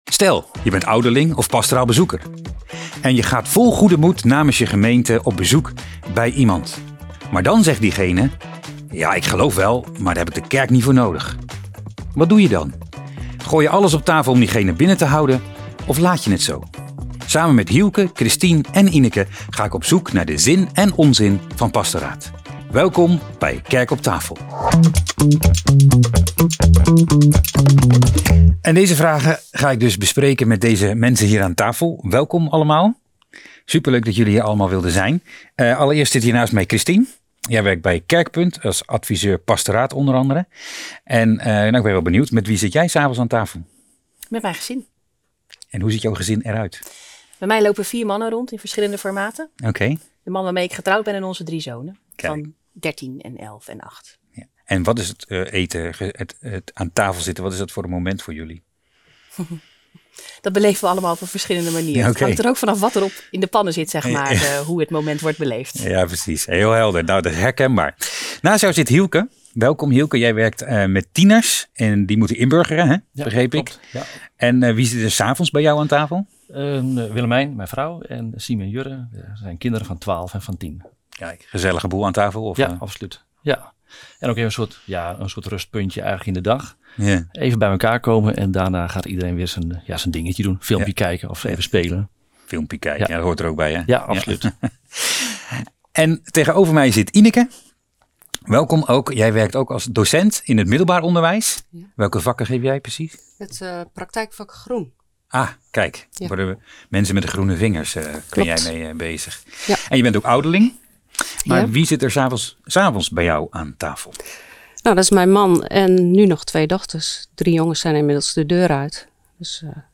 Dit is pilot aflevering 4 van Kerk op tafel, een talkshow waarbij allerlei kerk-gerelateerde onderwerpen aan bod komen.